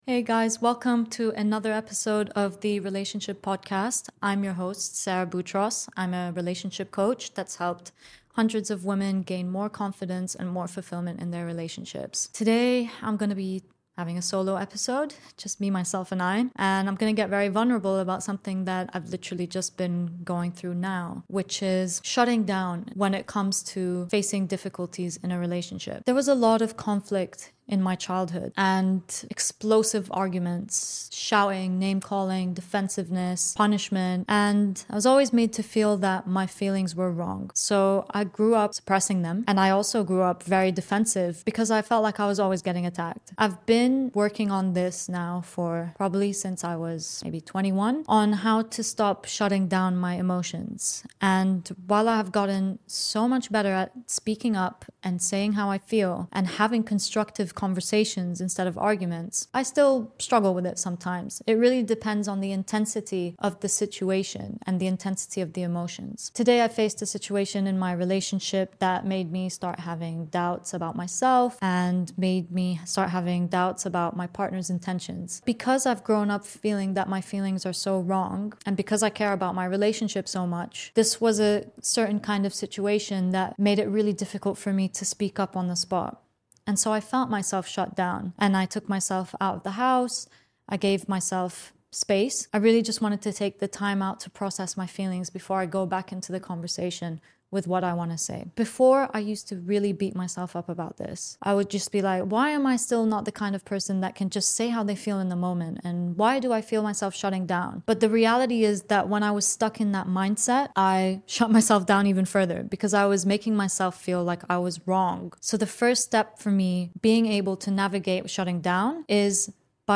and today, I'm diving deep into a solo episode where I get real and vulnerable about a topic that many can relate to: emotional shutdowns in our relationships during tough moments.